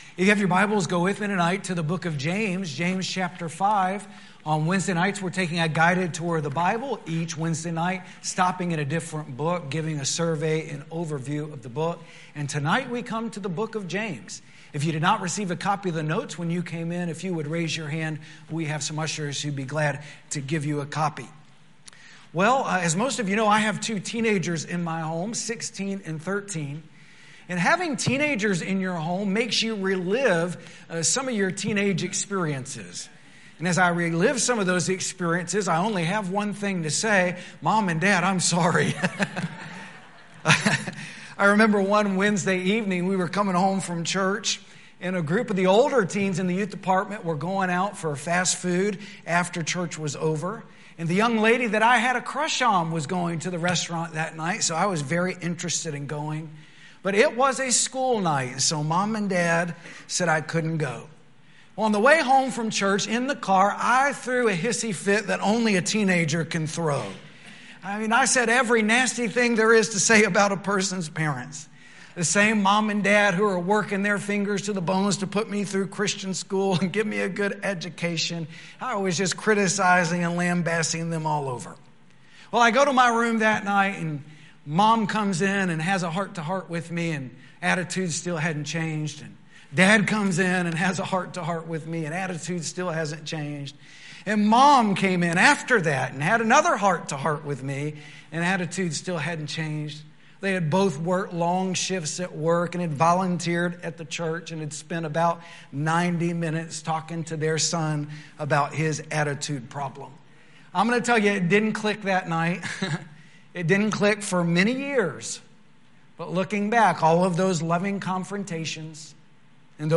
Sermon Audio - Media of Worth Baptist Church